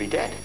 home *** CD-ROM | disk | FTP | other *** search / Horror Sensation / HORROR.iso / sounds / iff / yadead.snd ( .mp3 ) < prev next > Amiga 8-bit Sampled Voice | 1992-09-02 | 12KB | 1 channel | 26,128 sample rate | 0.05 seconds